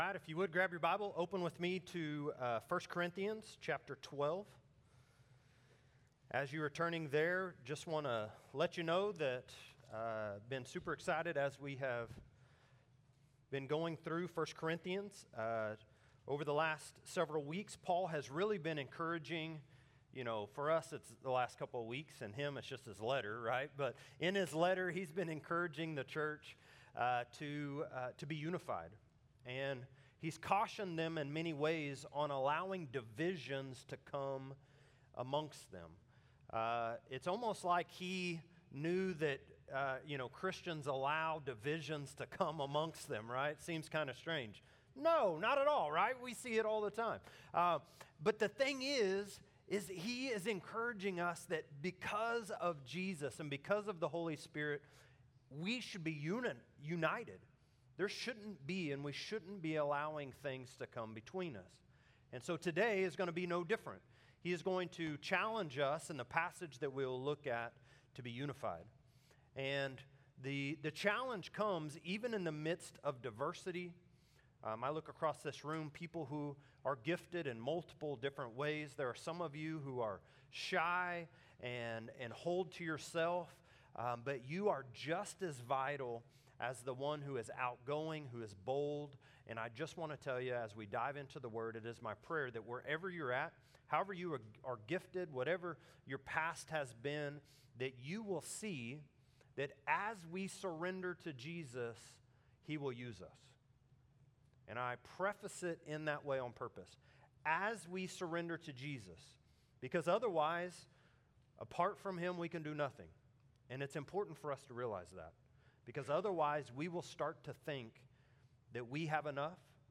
Messages | First Baptist Church Skiatook